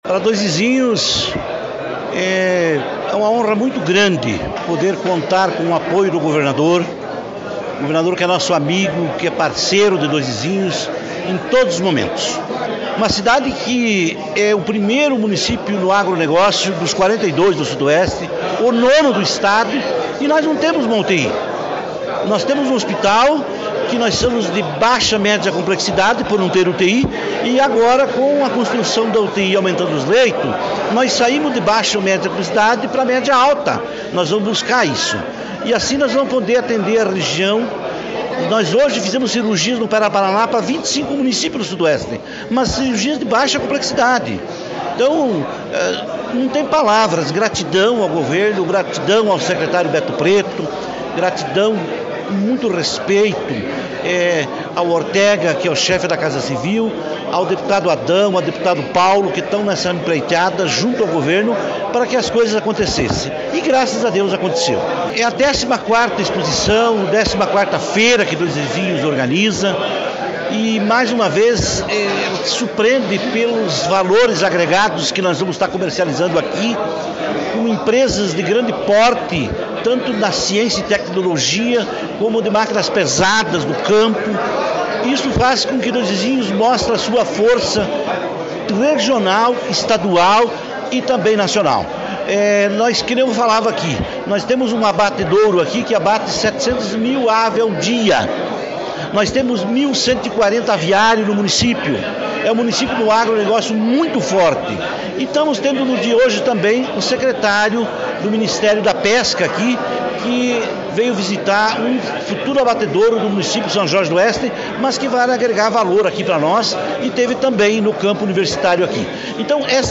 Sonora do prefeito de Dois Vizinhos, Carlinhos Turatto, sobre os investimentos para saúde na cidade e a abertura da Expovizinhos